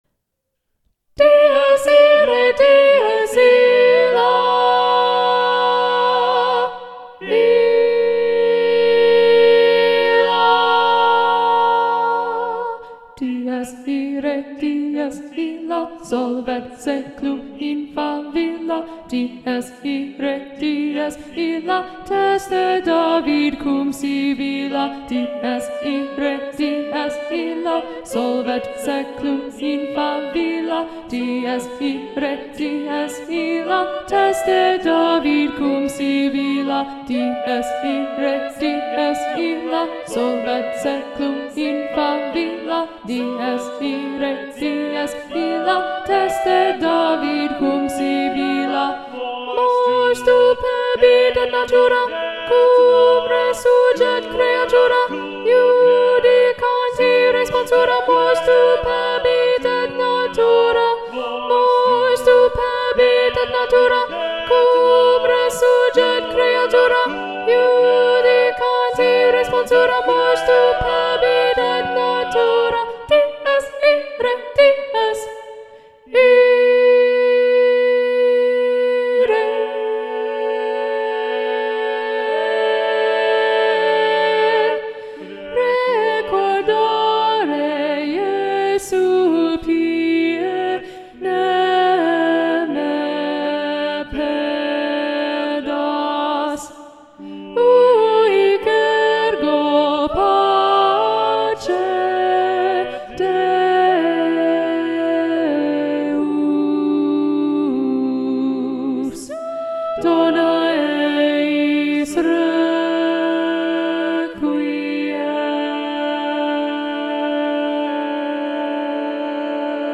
- Œuvre pour chœur à 8 voix mixtes (SSAATTBB)
SATB Alto 2 Predominant